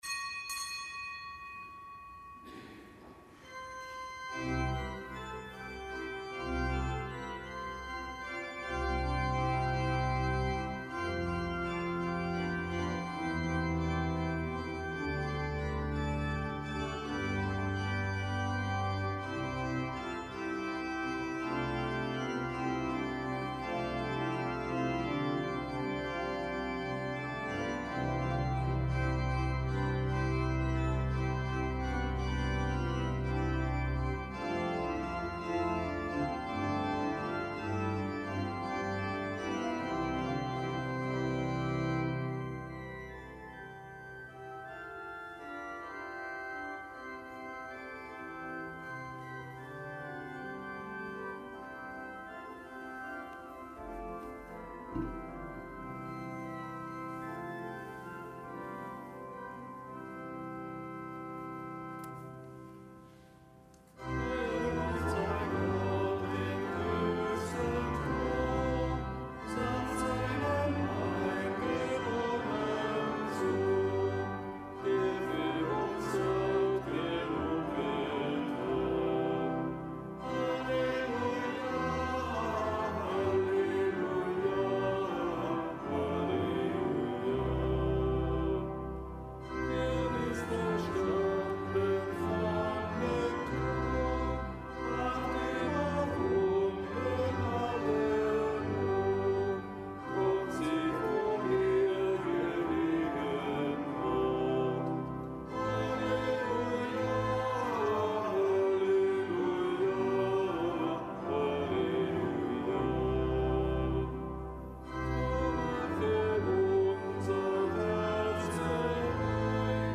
Kapitelsmesse aus dem Kölner Dom am Montag der vierten Osterwoche.